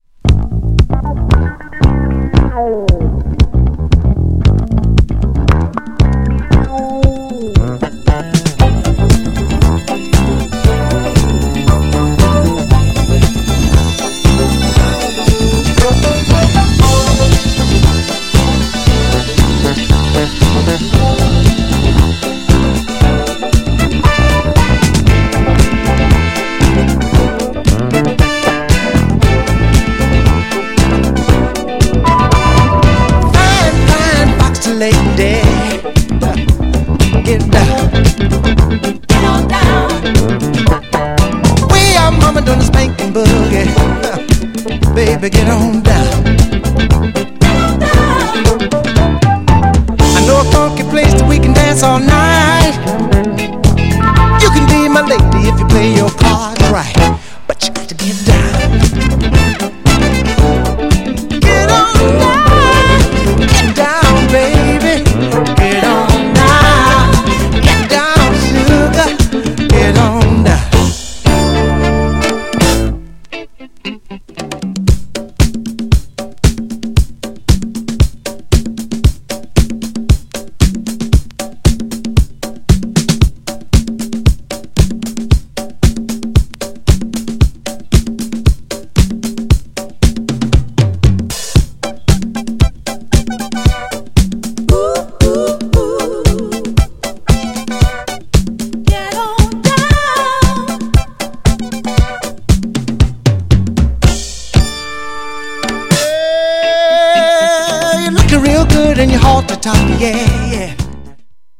ここ日本でもヒットした軽快なDISCO!!
GENRE Dance Classic
BPM 126〜130BPM
アップリフティング # ソウル # パーカッシブ # ライトファンク